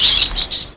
rat.wav